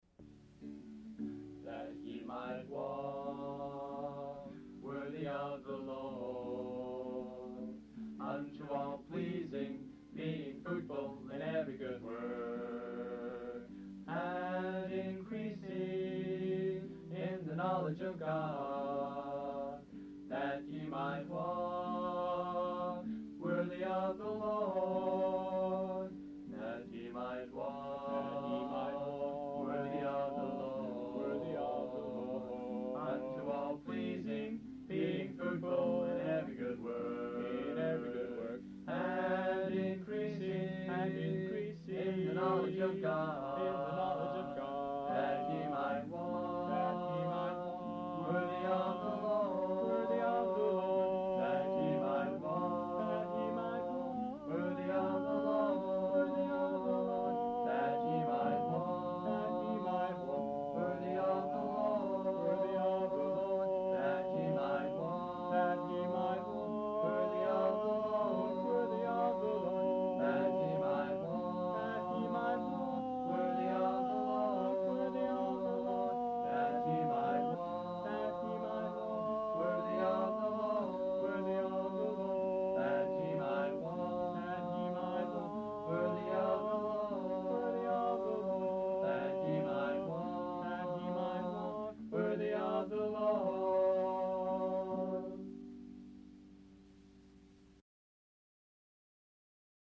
With joyful expectancy